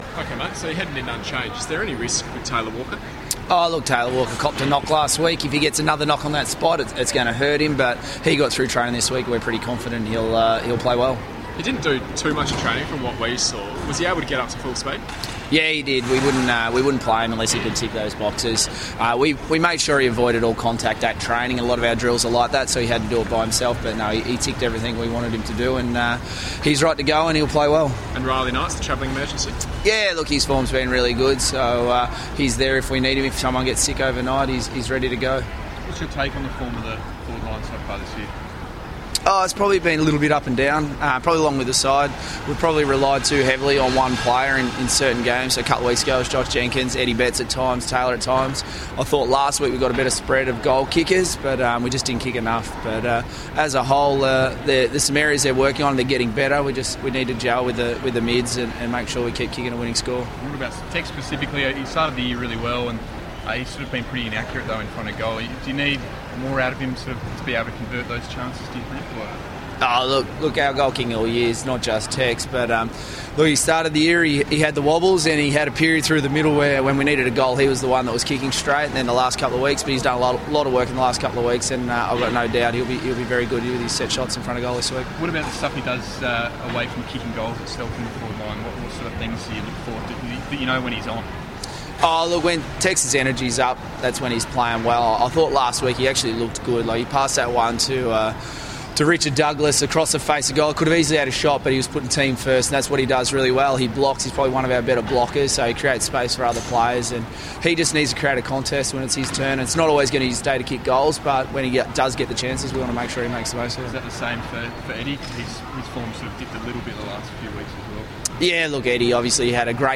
David Teague Press Conference